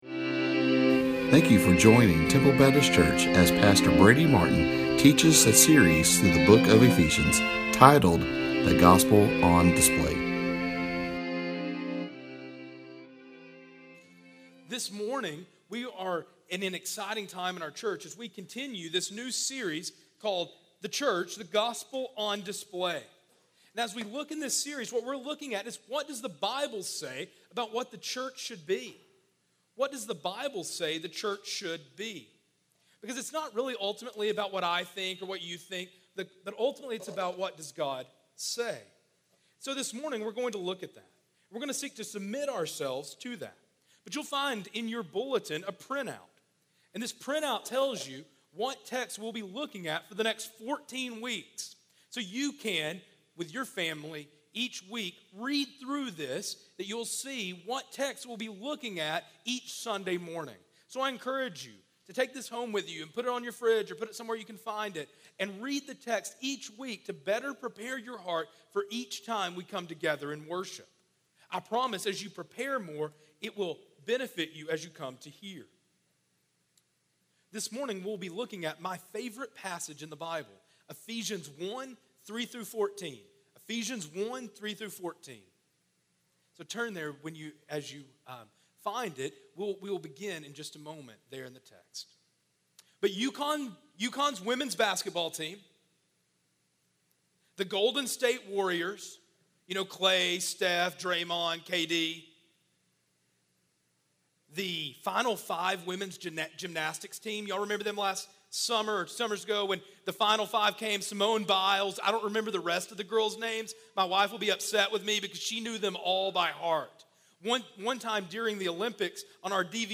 Each text is seen in light of how God wants to put the Gospel on display in our lives, our homes, and in our church. Please listen to the sermons and we pray that God will use them for your good and His glory!